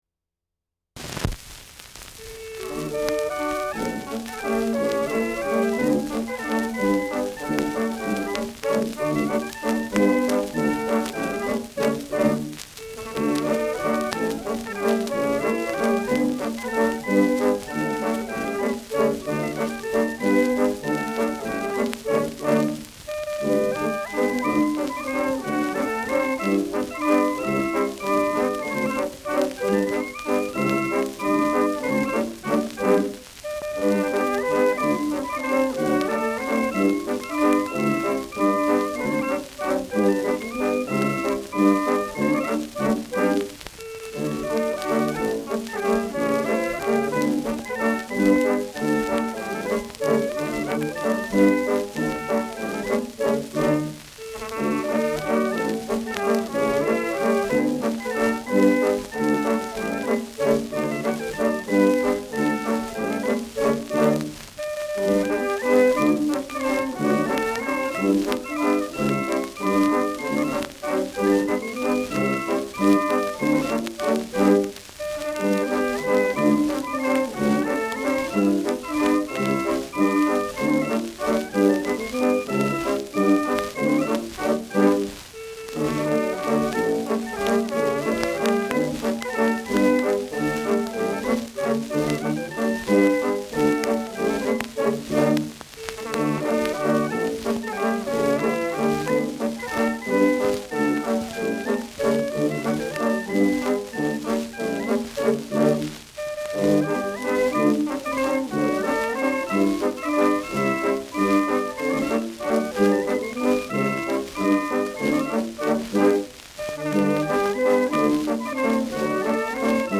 Schellackplatte
Stärkeres Grundrauschen : Gelegentlich leichtes bis stärkeres Knacken
Kapelle Jais (Interpretation)
[München] (Aufnahmeort)